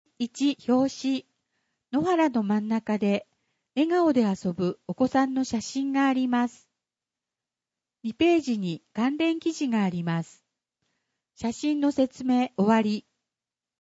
「広報あづみの」を音声でご利用いただけます。